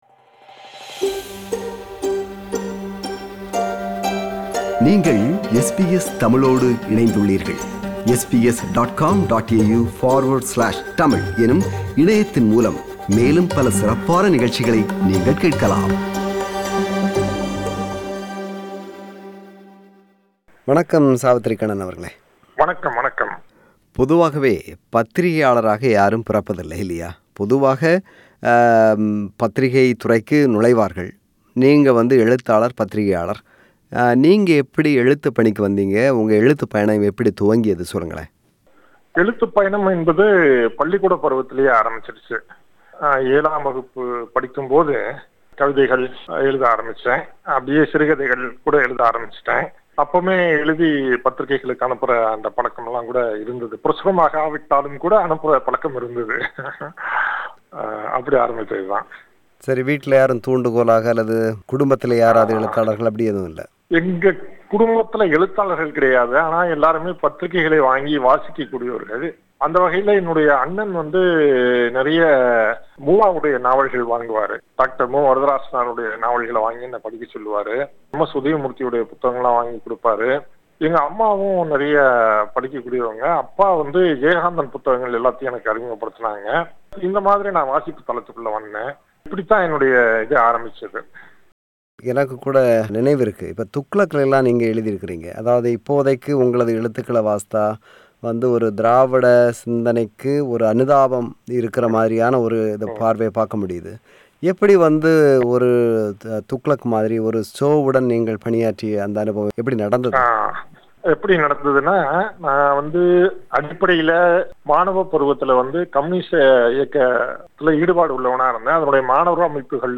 அவரை சந்தித்து உரையாடுகிறார்